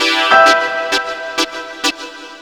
SYNTHLOOP3-L.wav